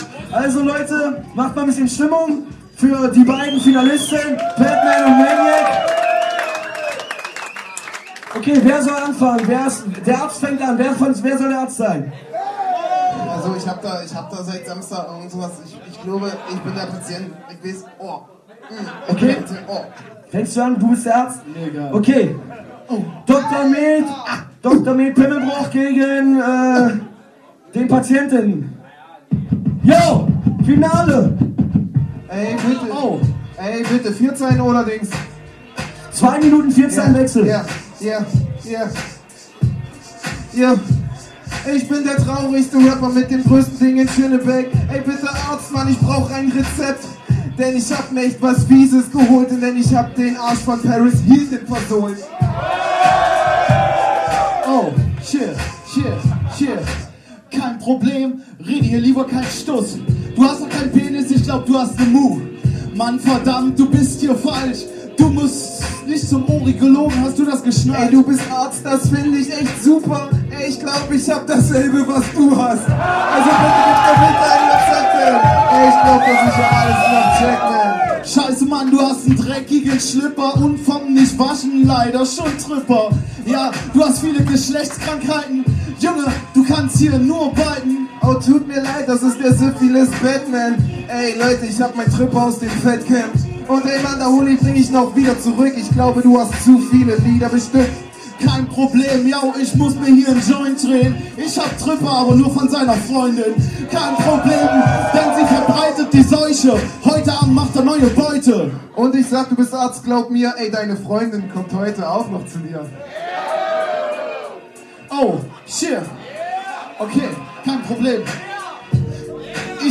an den Turntables